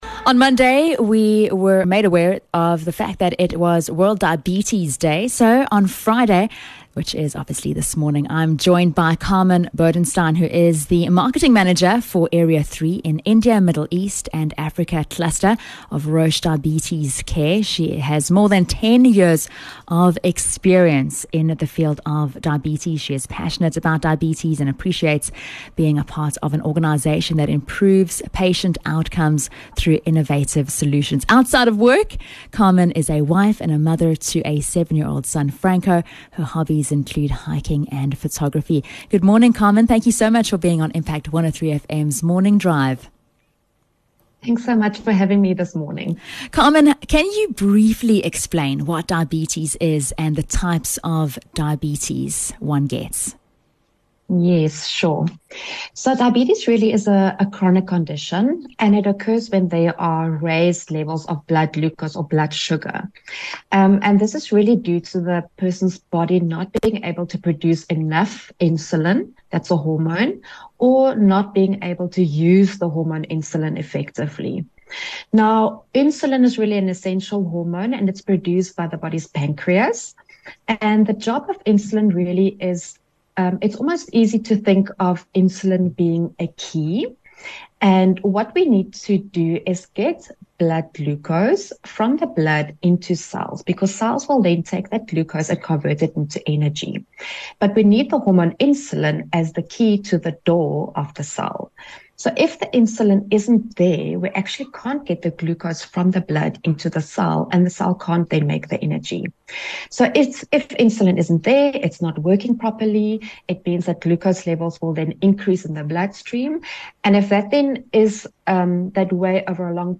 18 Nov World Diabetes Day - Interview